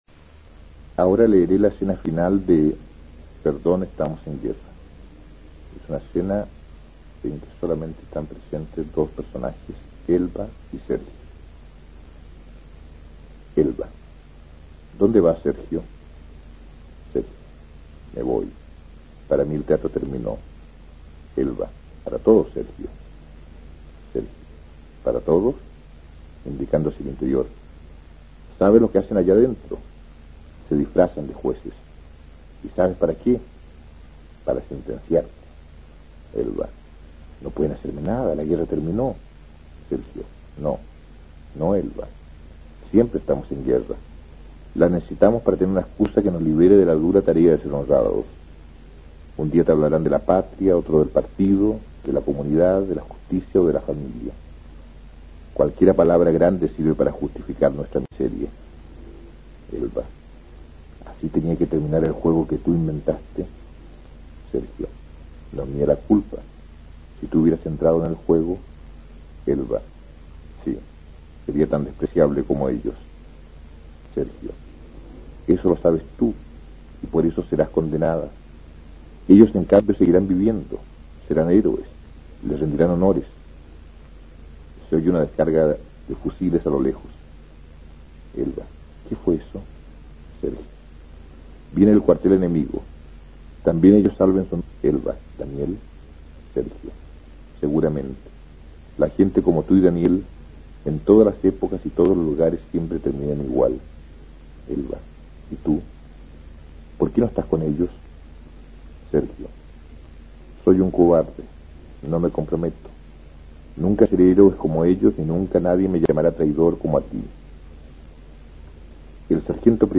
Aquí podrás escuchar al prolífico dramaturgo chileno Sergio Vodanovic leyendo la escena final de su obra "Perdón, estamos en guerra" (1966).